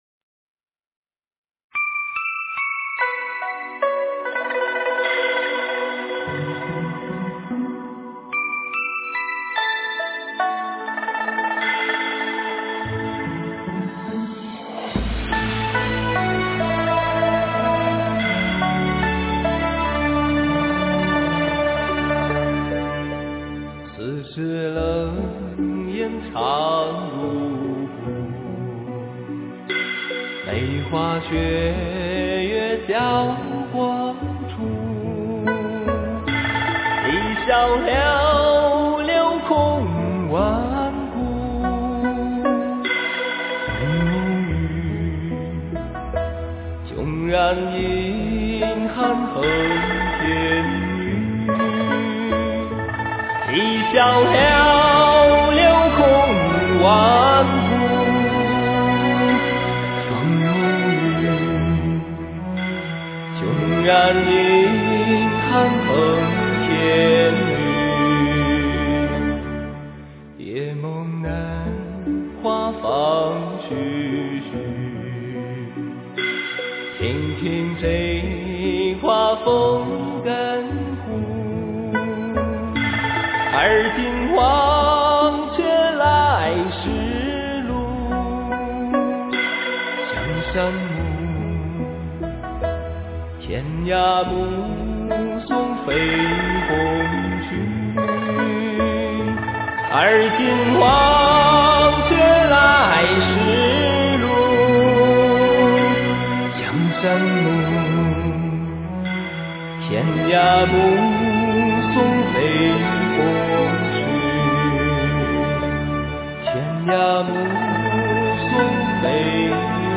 佛音 诵经 佛教音乐 返回列表 上一篇： 忏悔文 下一篇： 般若三昧 相关文章 剑心--张杰 剑心--张杰...